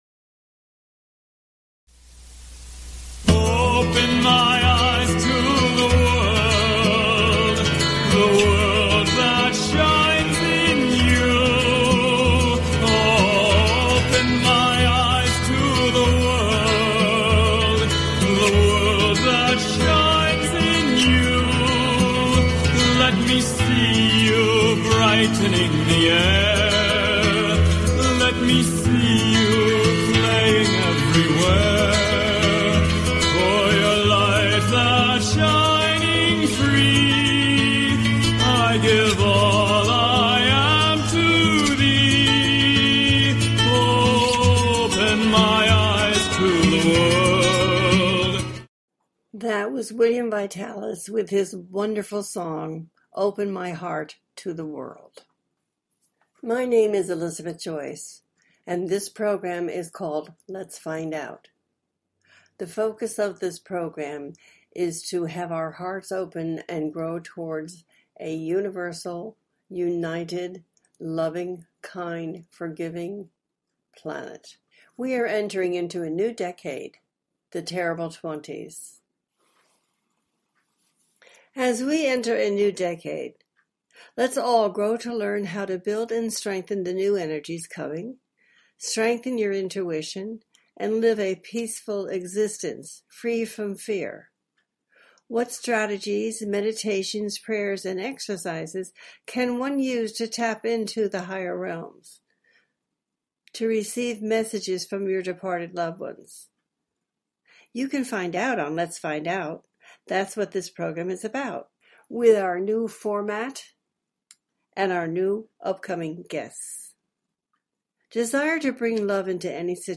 Talk Show
The listener can call in to ask a question on the air.